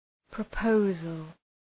Προφορά
{prə’pəʋzəl}